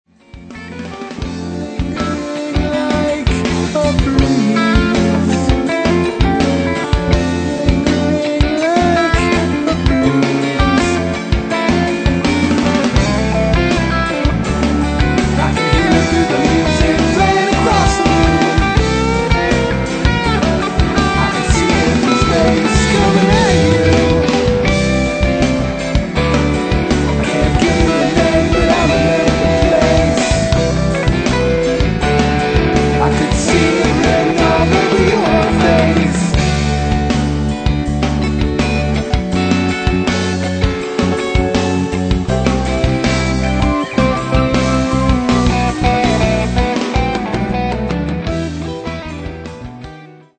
Experimental Rock Three Piece
- compositions, vocals, guitar
- drums
- bass, keyboards, guitar
recorded at Midtown Recording Studio